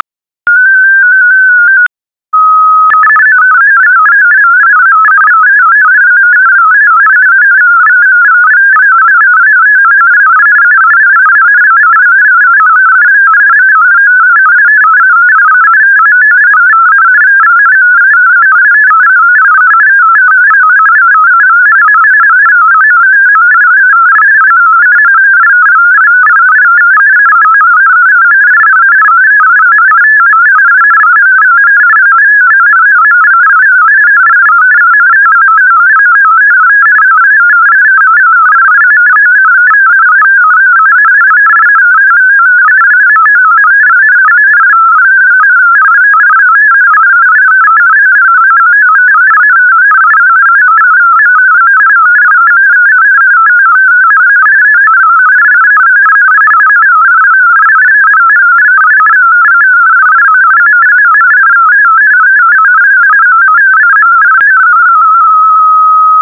In this exercise, you will receive a simulated digital transmission with fldigi that is not formatted for display in flmsg.